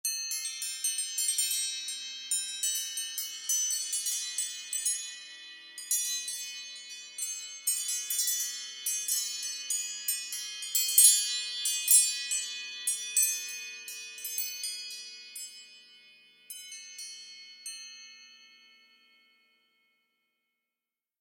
Chimes.mp3